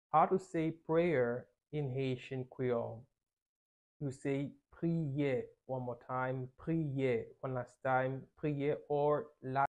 How to say "Prayer" in Haitian Creole - "Priyè" pronunciation by a native Haitian Teacher
“Priyè” Pronunciation in Haitian Creole by a native Haitian can be heard in the audio here or in the video below: